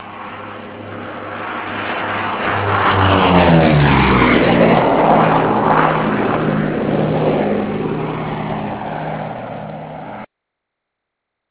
Listen here! P-38 Flyby!